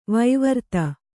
♪ vaivarta